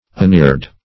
Uneared \Un*eared"\, a. Not eared, or plowed.